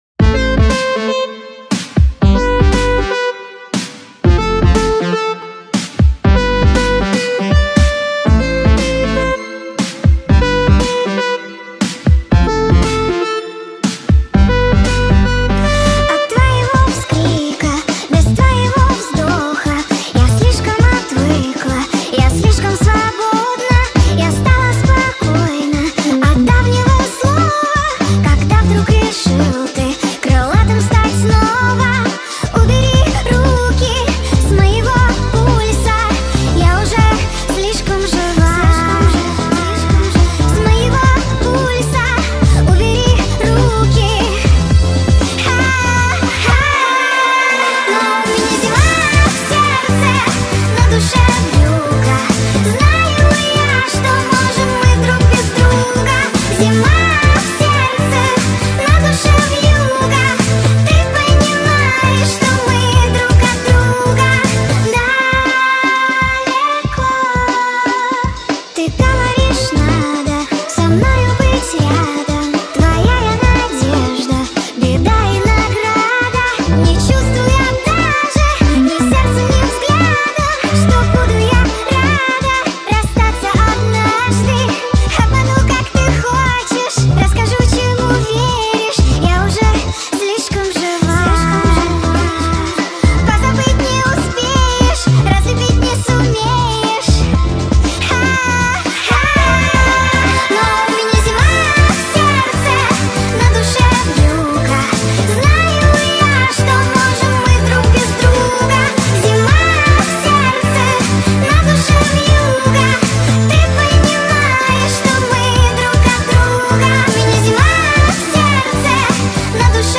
TikTok Remix Speed Up